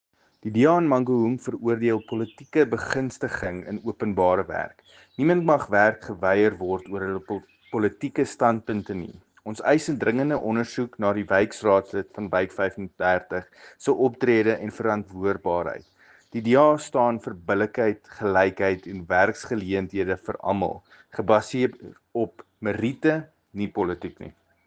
Afrikaans soundbite by Cllr Paul Kotzé.